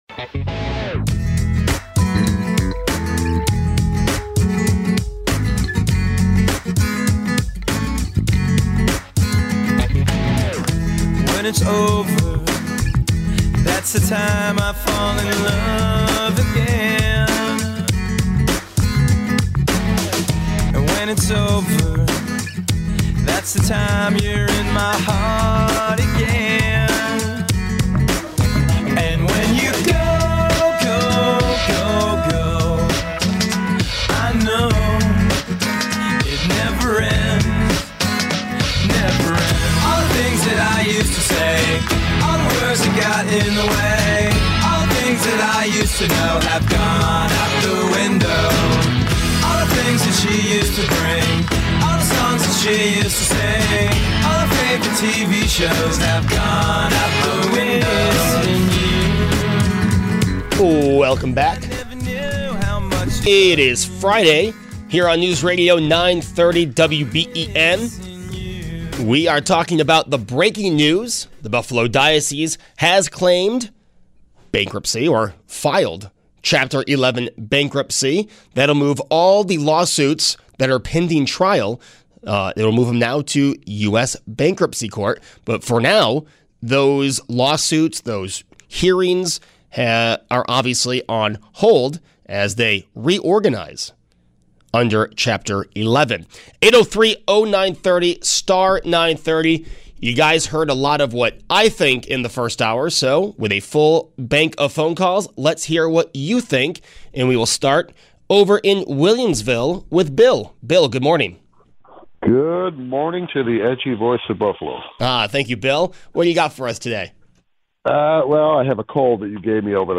Taking your calls on the Breaking News that the Diocese of Buffalo has filed for Chapter 11 Bankruptcy.